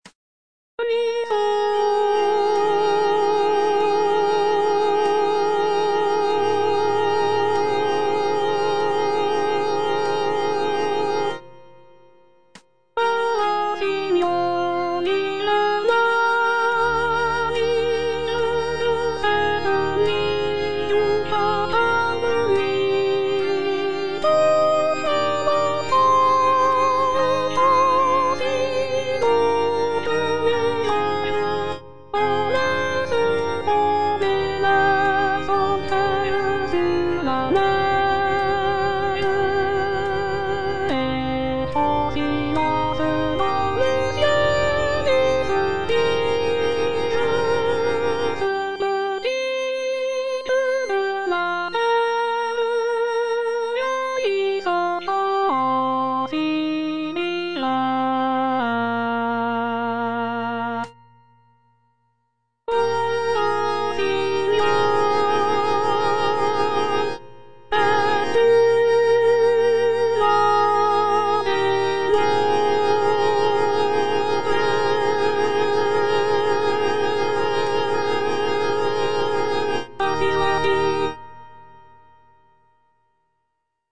Mezzosoprano/Soprano I (Voice with metronome)
choral work